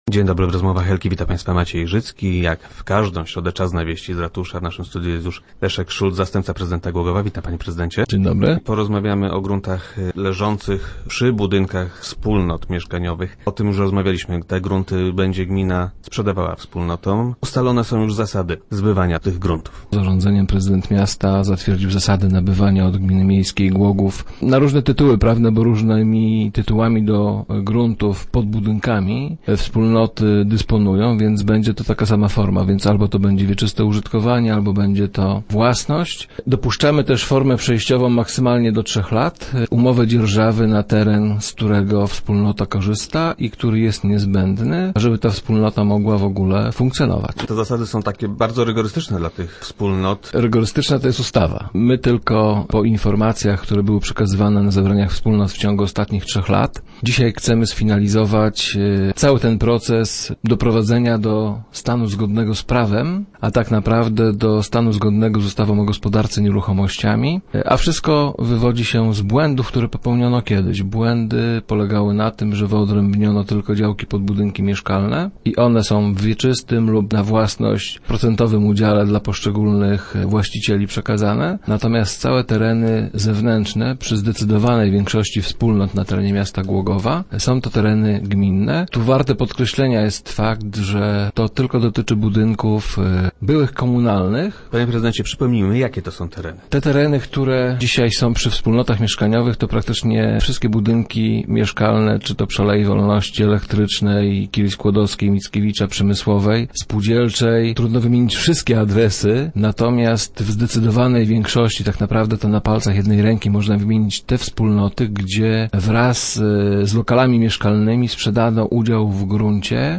- W najbliższym czasie poinformujemy o nich wszystkich właścicieli - informuje Leszek Szulc, zastępca prezydenta Głogowa, który był gościem Rozmów Elki.